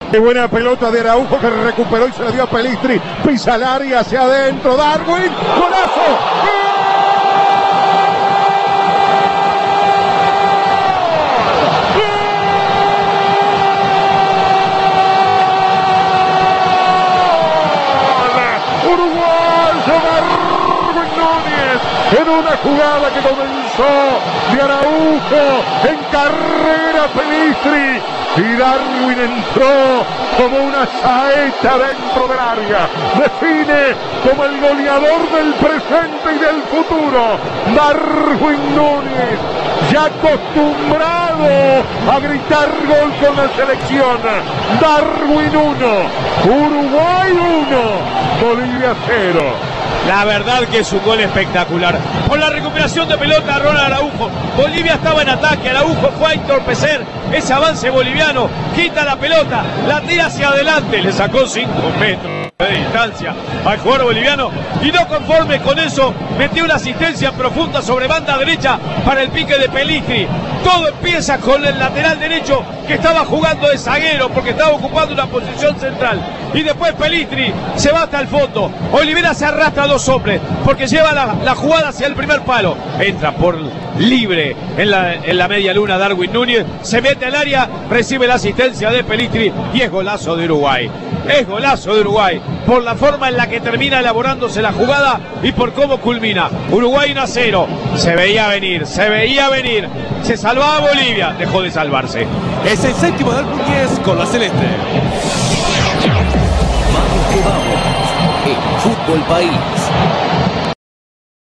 La victoria celeste en la voz del equipo de Vamos que Vamos